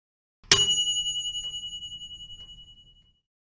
toaster oven ding - sound effect.mp3